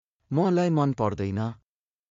当記事で使用された音声（ネパール語および日本語）は全てGoogle翻訳　および　Microsoft TranslatorNative Speech Generation、©音読さんから引用しております。